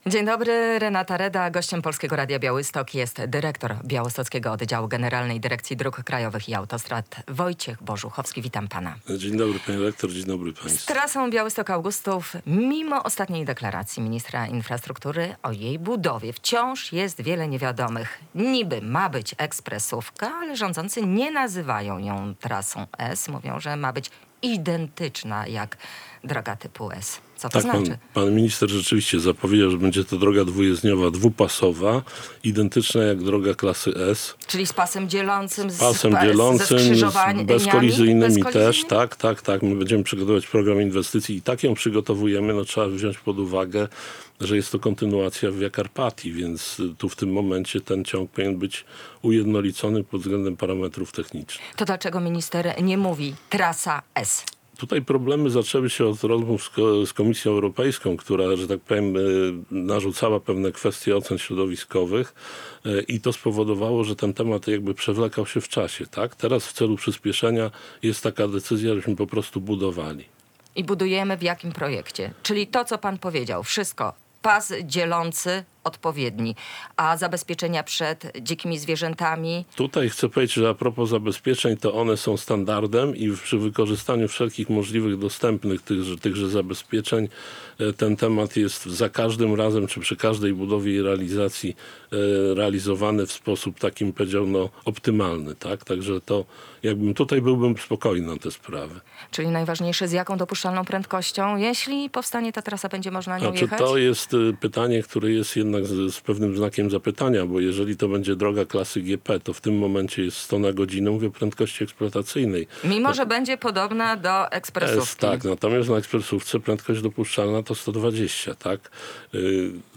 Trasa Białystok-Augustów będzie mieć wszystkie parametry ekspresówki. Zapewniał w Rozmowie Dnia w Polskim Radiu Białystok dyrektor białostockiego oddziału Generalnej Dyrekcji Dróg Krajowych i Autostrad Wojciech Borzuchowski.